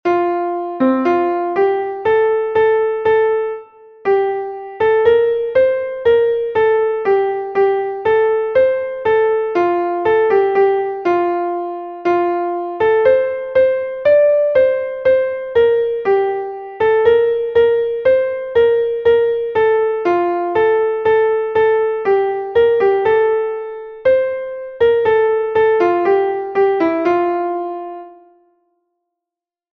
Text und Melodie: Volkslied aus Franken